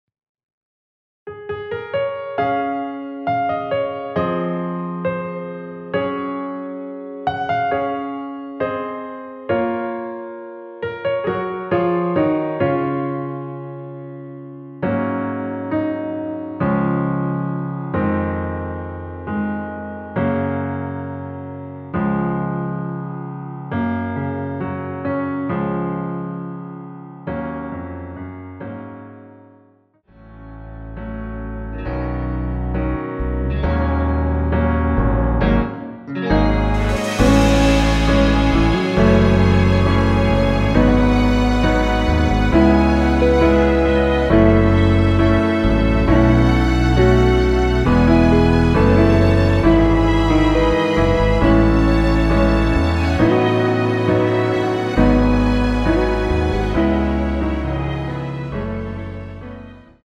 원키에서(-1)내린 MR입니다.
Db
앞부분30초, 뒷부분30초씩 편집해서 올려 드리고 있습니다.
중간에 음이 끈어지고 다시 나오는 이유는